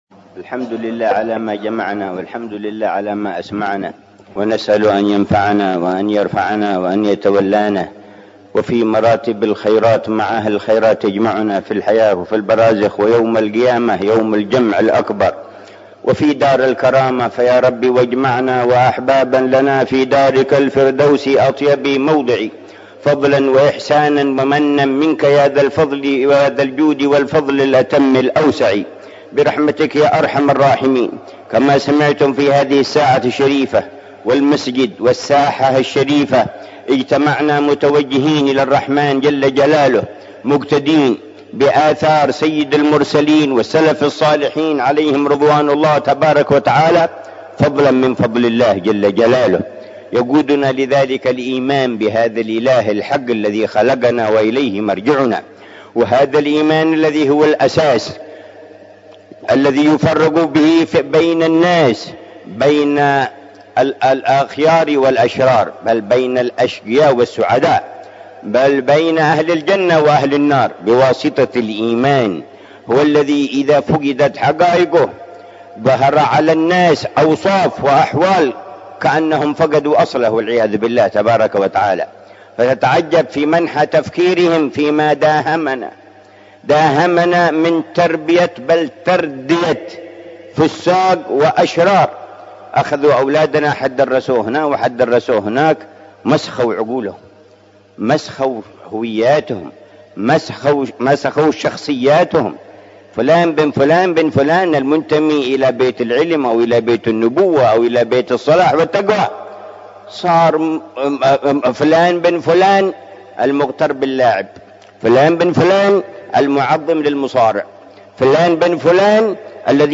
مذاكرة في المولد السنوي في مسجد الإمام عبدالرحمن السقاف
مذاكرة العلامة الحبيب عمر بن محمد بن حفيظ في المولد السنوي في مسجد الإمام عبدالرحمن بن محمد السقاف، بمدينة تريم، عصر الجمعة 27 ربيع الأول 1442هـ